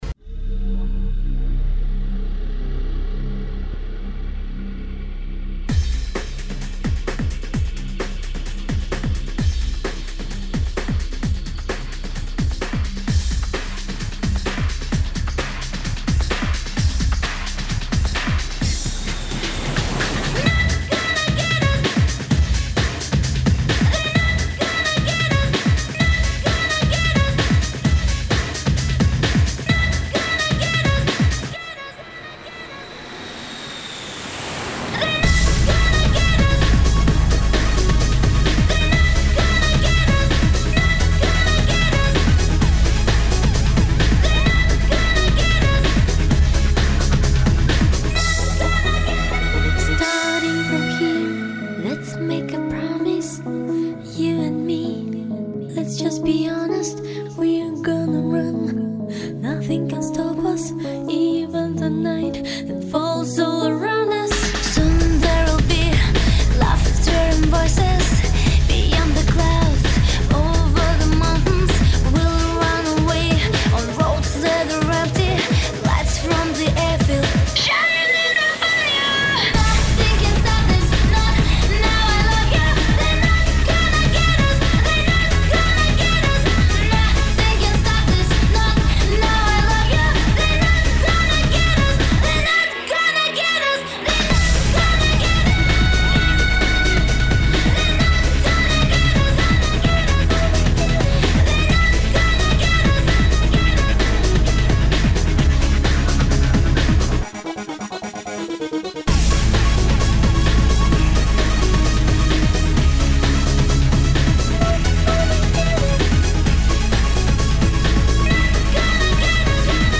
Genre: Dream.